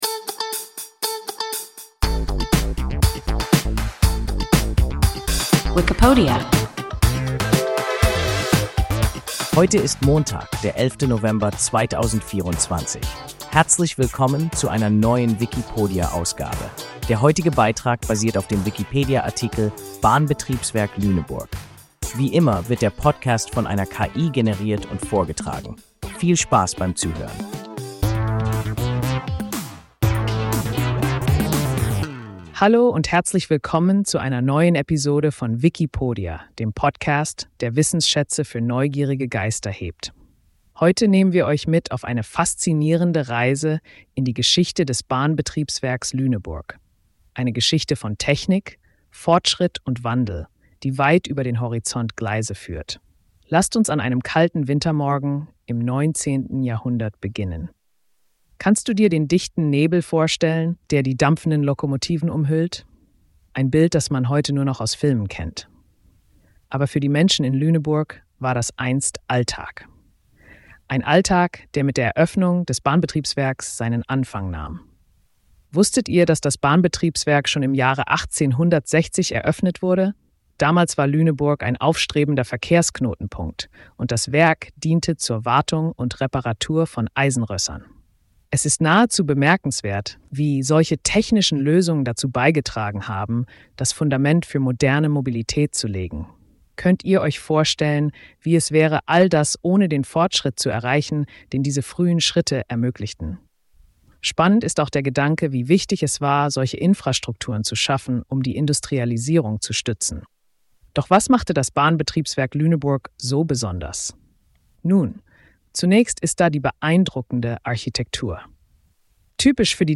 Bahnbetriebswerk Lüneburg – WIKIPODIA – ein KI Podcast